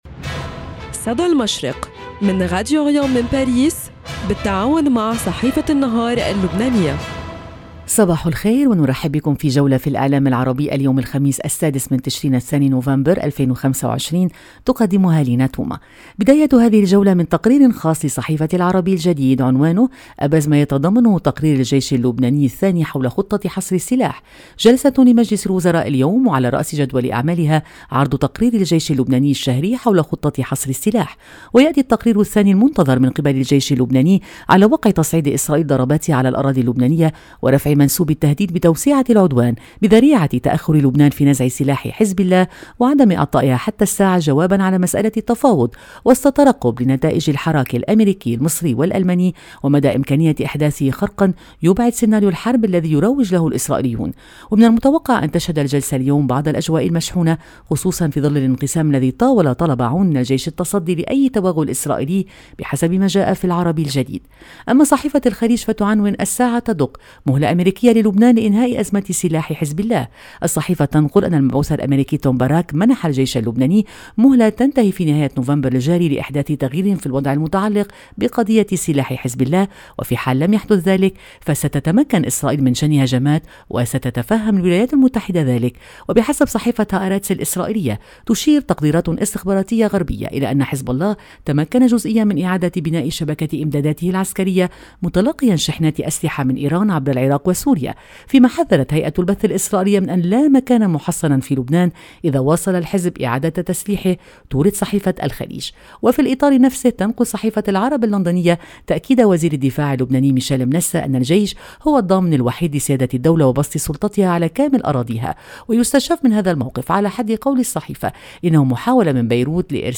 صدى المشرق – نافذتكم اليومية على إعلام الشرق، كل صباح في تعاون بين راديو أوريان إذاعة الشرق من باريس مع جريدة النهار اللبنانية، نستعرض فيها أبرز ما جاء في صحف ومواقع الشرق الأوسط والخليج العربي من تحليلات مواقف وأخبار،  لنرصد لكم نبض المنطقة ونحلل المشهد الإعلامي اليومي.